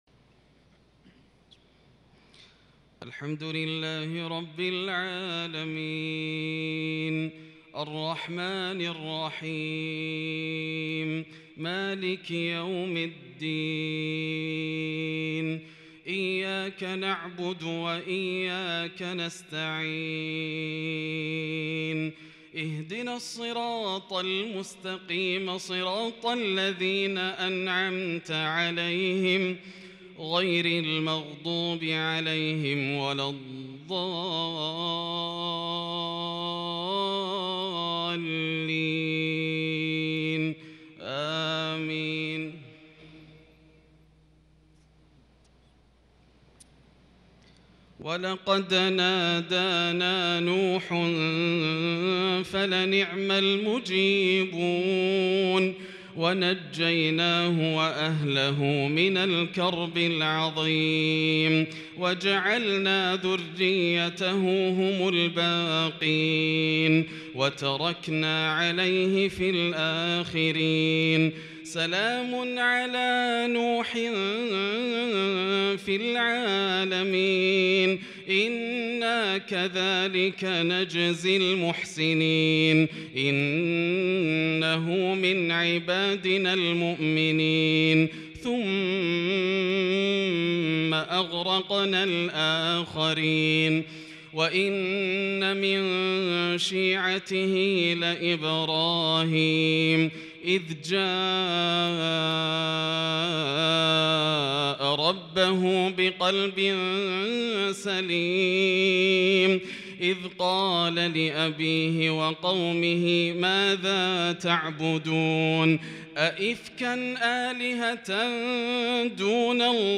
صلاة العشاء للشيخ ياسر الدوسري 9 ذو الحجة 1442 هـ
تِلَاوَات الْحَرَمَيْن .